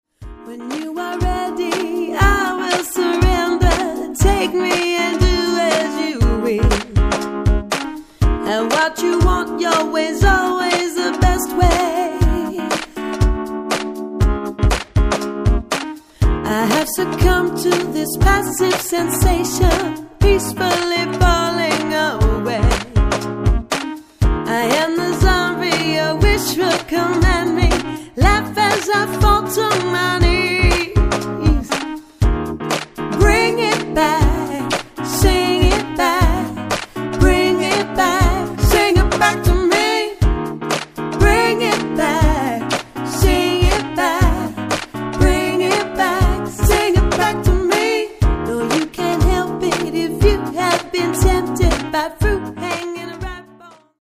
Performing Classic R&B hits from the worlds great artists
Demo’s
Soul/R&B artists